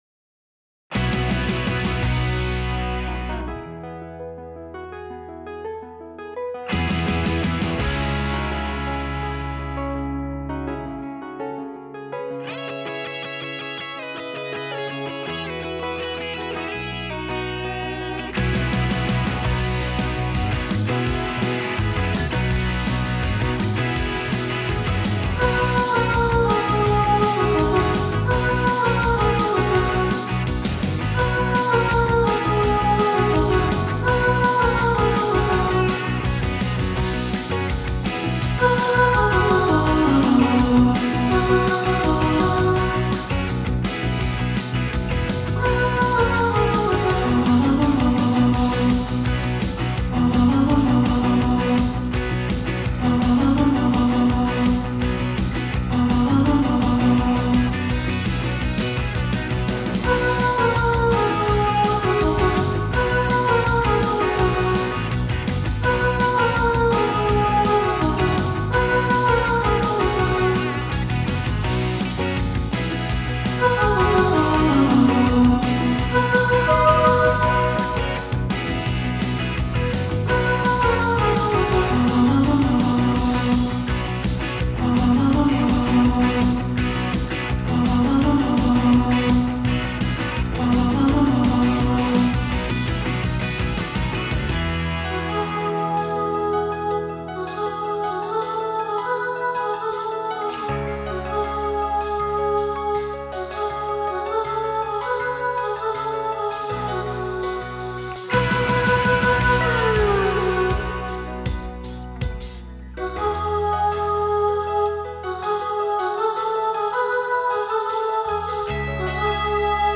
Hole-In-Your-Soul-Soprano-Solo | Ipswich Hospital Community Choir
Hole-In-Your-Soul-Soprano-Solo.mp3